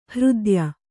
♪ hřdya